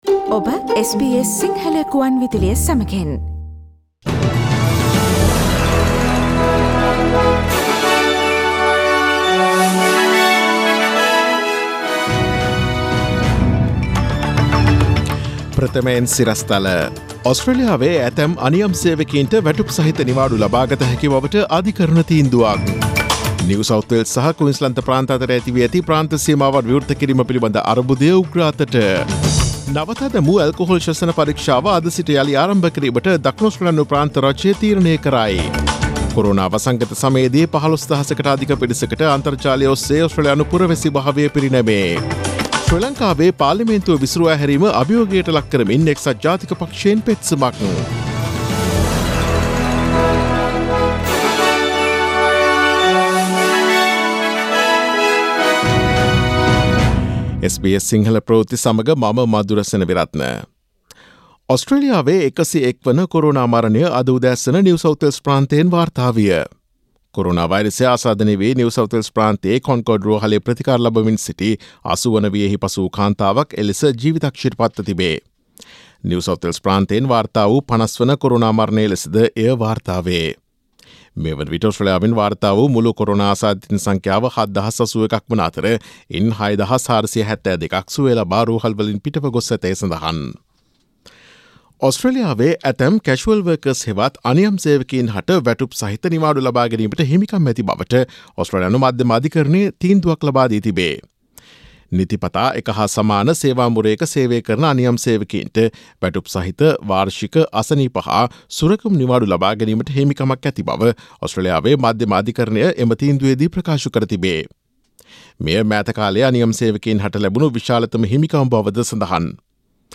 Daily News bulletin of SBS Sinhala Service: Friday 22 May 2020